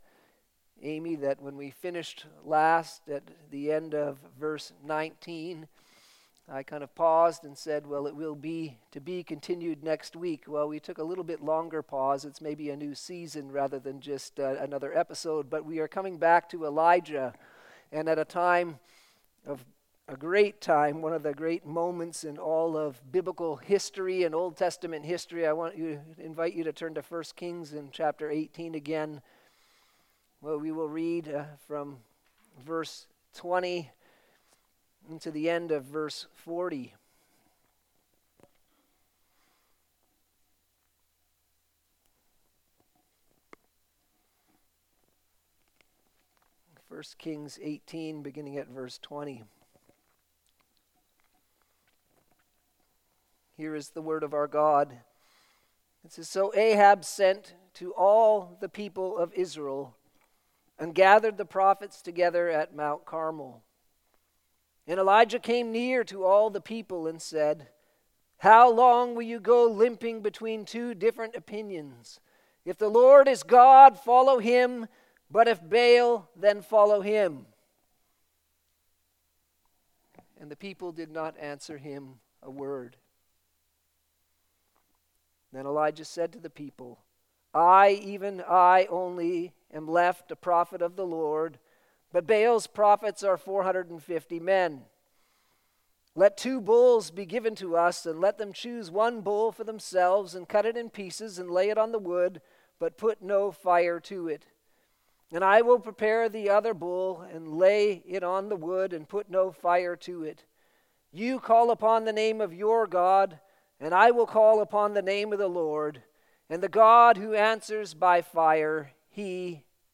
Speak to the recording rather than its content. Evening Service - 1 Kings 18: 20-40